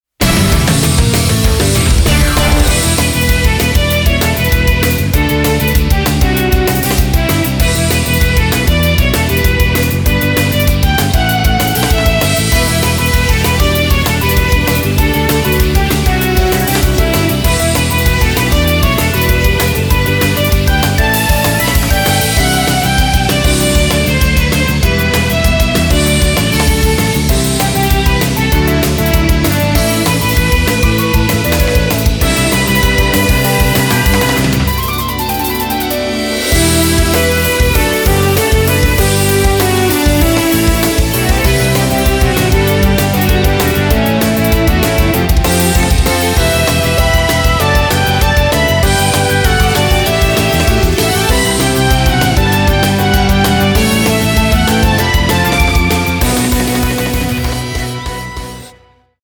■ Background Music